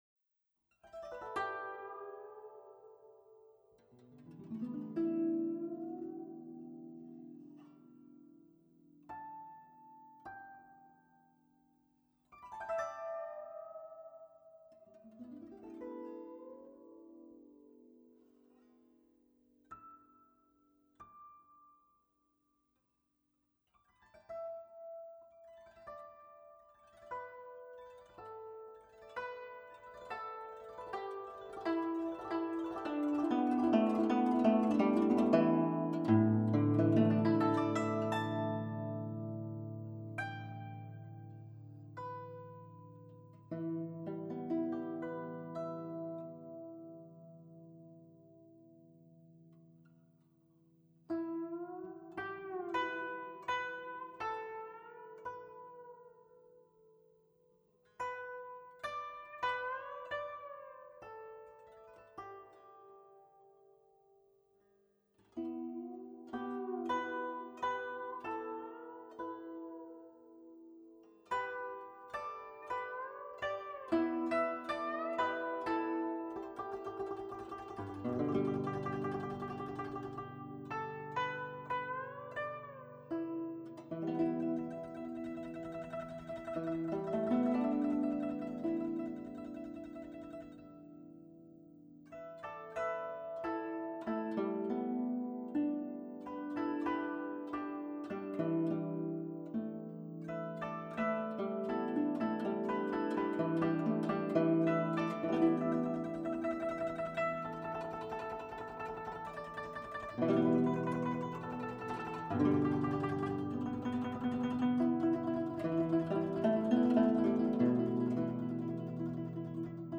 PISTES AUDIO GUZHENG: